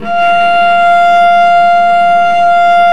Index of /90_sSampleCDs/Roland L-CD702/VOL-1/STR_Vc Marc&Harm/STR_Vc Harmonics